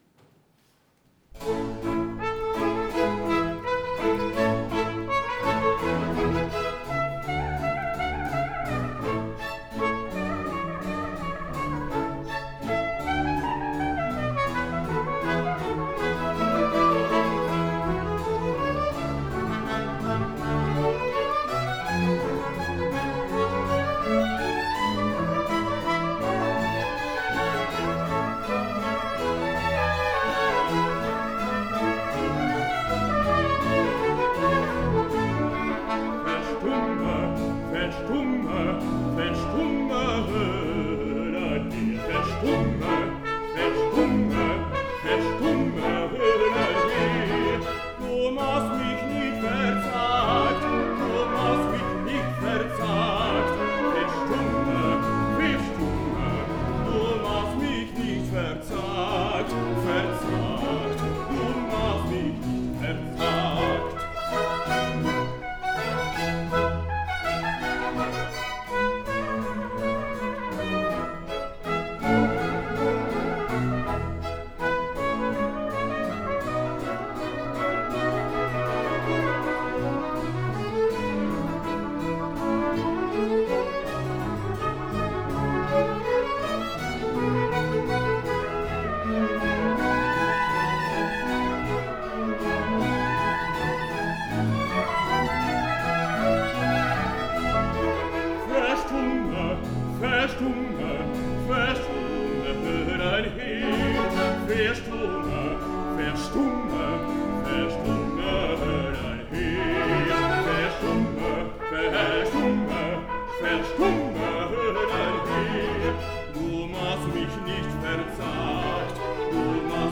En attendant, voici un écho du concert donné le 2 novembre 2014. Il s’agit de la Cantate BWV 5 « Wo soll ich fliehen hin ».
sopranos
trompette à coulisse et trompette naturelle
hautbois
violoncelle
clavecin